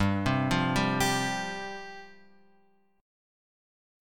G 7th Suspended 4th